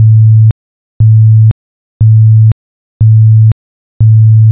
Avisador acústico electrónico
Rango de frecuencia acústica: 110-520 Hz
110Hz intermitente
110Hz intermitente.wav